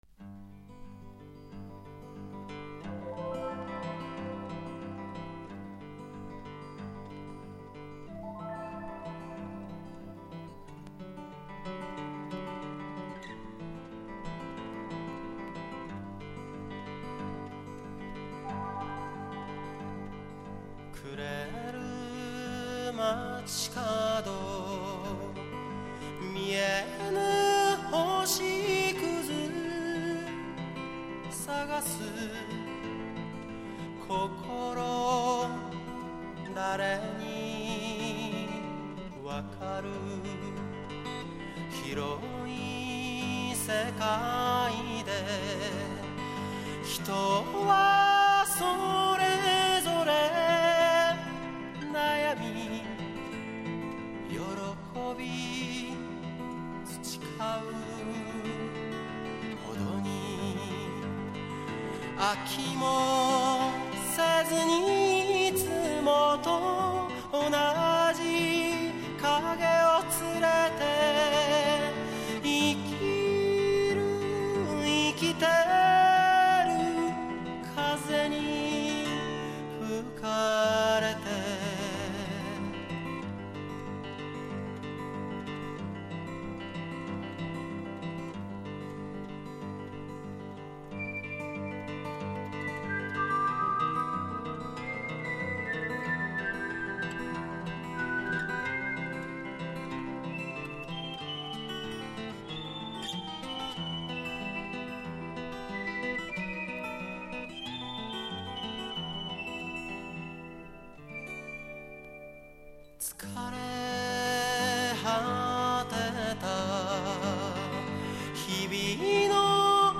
ＬＰのアナログ音源をデジタル音源へ
音源はレコード盤から収集しました。
埃による特有のノイズが載っていますが、音楽CDの作成出来ます